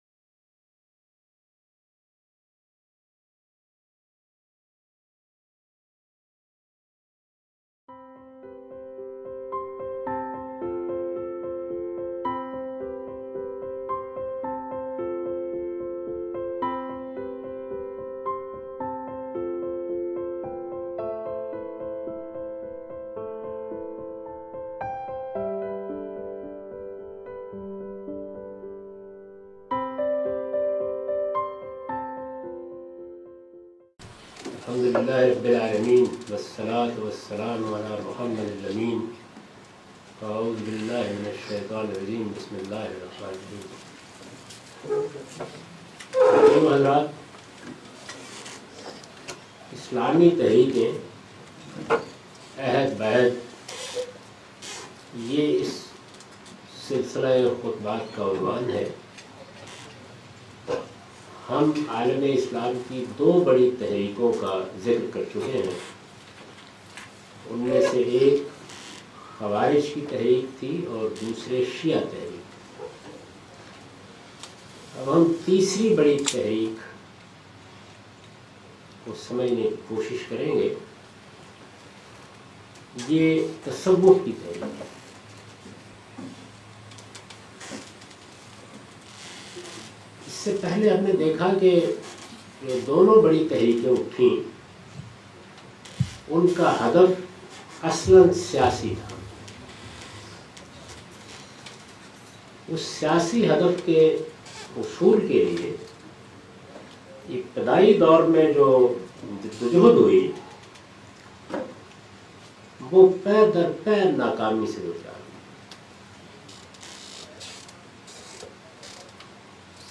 This series of lectures was recorded in Australia in January 2014.